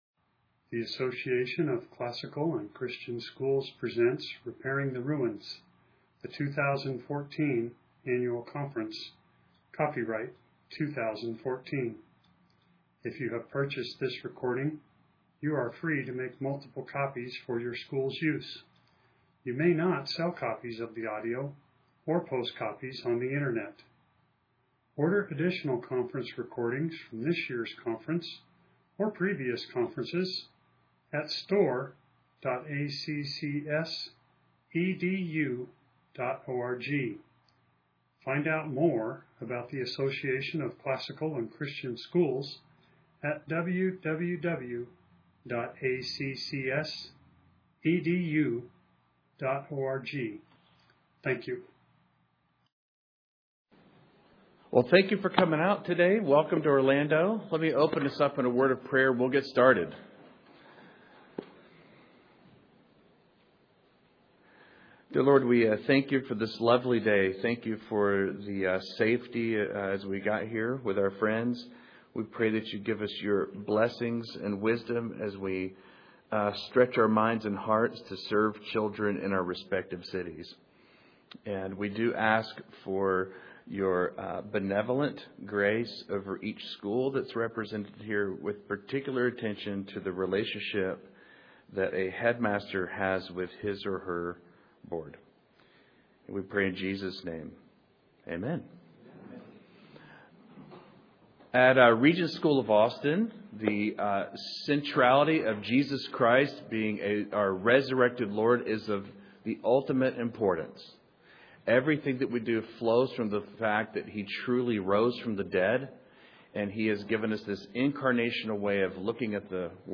2014 Leaders Day Talk | 2014 | Leadership & Strategic
Beyond practices and principles, we will focus on the art of formal and informal communication between the board and head as well. Speaker Additional Materials The Association of Classical & Christian Schools presents Repairing the Ruins, the ACCS annual conference, copyright ACCS.